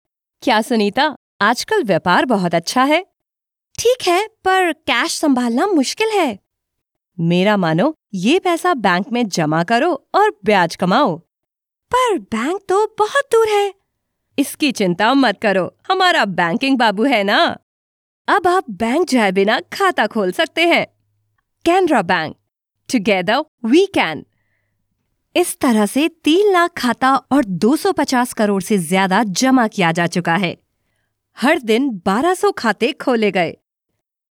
动画角色【多变声线】